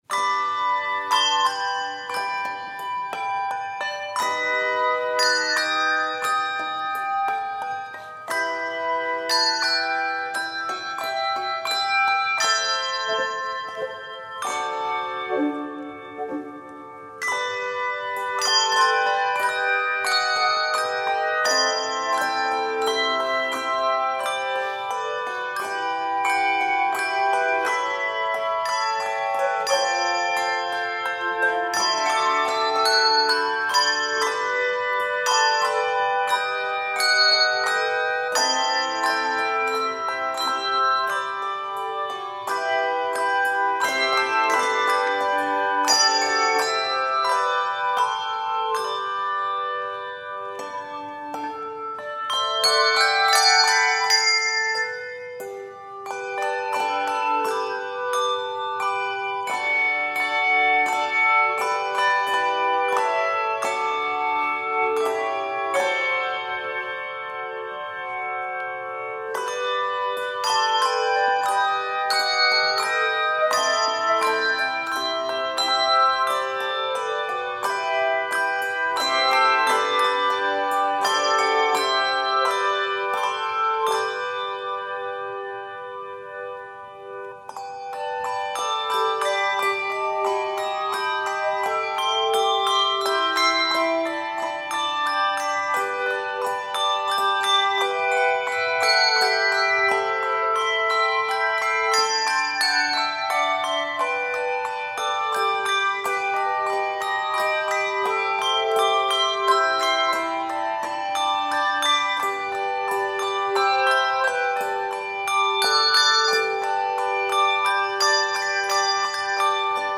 sensitive arrangement